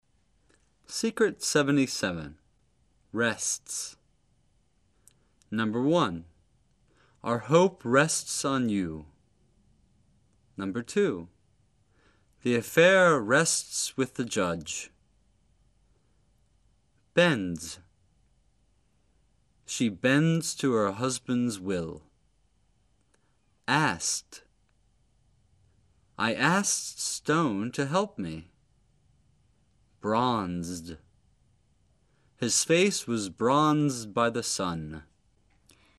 rest + s= rests[rZsts]  寄托；仍然
此发音分两个主要整体部分：先发第一整体部分[rZs]，然后再发第二整体部分[ts]。
bend + s= bends[bZndz]     屈服；顺从
此发音分两个主要整体部分：先发第一整体部分[bZn]，然后再发第二整体部分[dz]。
ask + ed= asked[Askt]        请
bronze + d= bronzed [brBnzd]  褐色